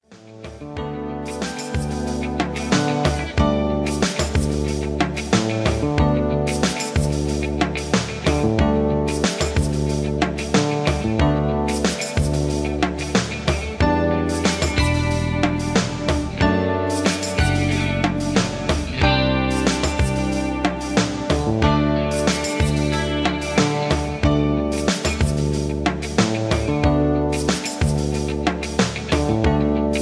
(Key-D) Karaoke MP3 Backing Tracks
Just Plain & Simply "GREAT MUSIC" (No Lyrics).
karaoke mp3 tracks